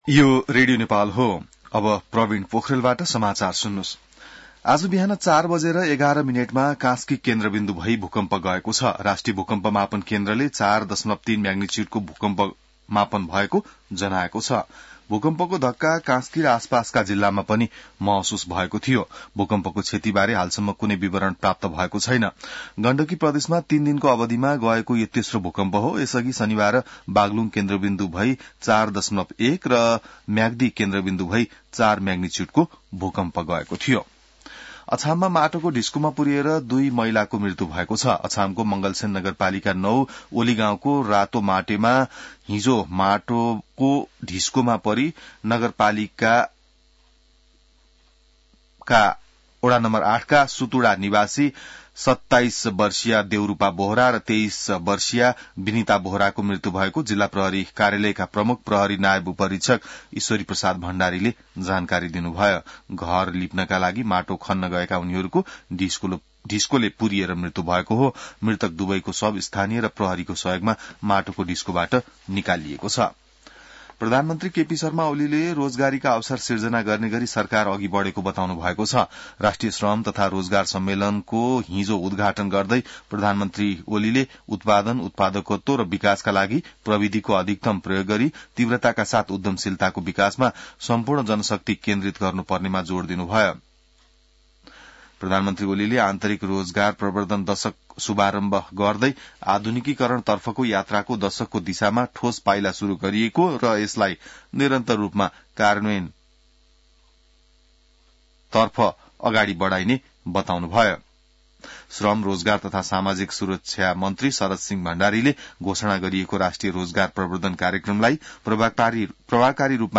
बिहान ६ बजेको नेपाली समाचार : २८ फागुन , २०८१